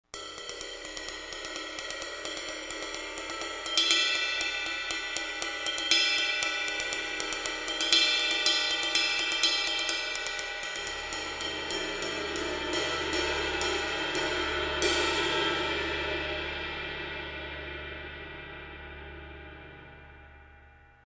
[demande d'infos] ride Meinl Byzance dark
Je la trouve précise, polyvalente, puissante quand on la violente un peu. Par contre, la cloche est petite et pas très puissante.
Le ping est défini.
Mais pour les samples je trouve celui de cymbalsonly très fidèle joué avec le kit et celui de youtube aussi malgré le son approximatif mais très juste sur la dynamique de la cymbale.